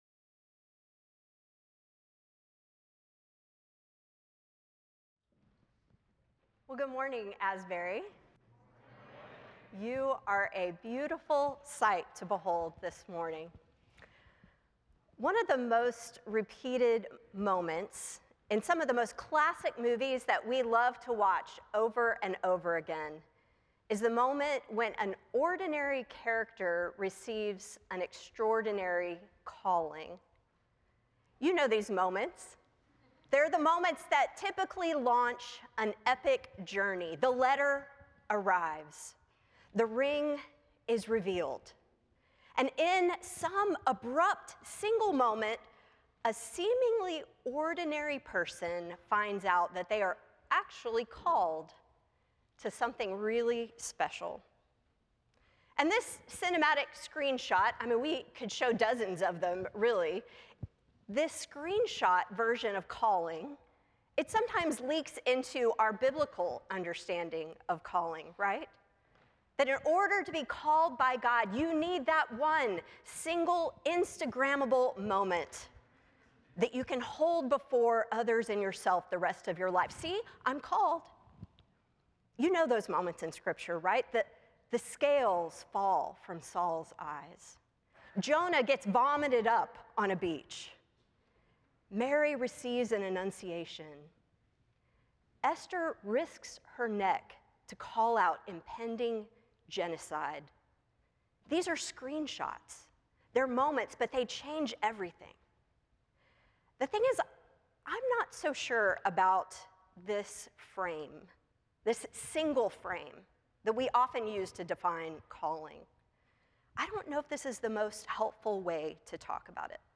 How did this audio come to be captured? The following service took place on Tuesday, March 3, 2026.